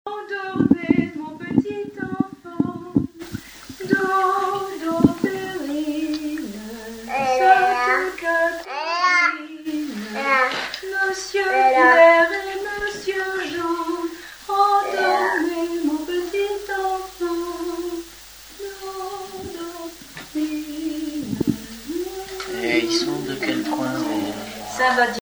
enfantine : berceuse
Chansons traditionnelles et populaires
Pièce musicale inédite